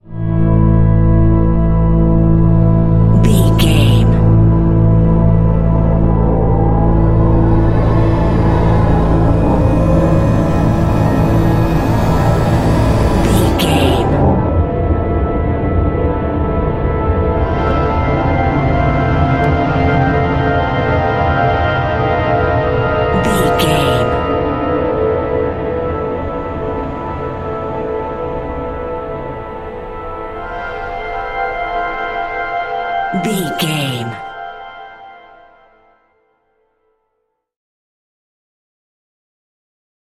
Atonal
Slow
tension
ominous
eerie
strings
synthesiser
ambience
pads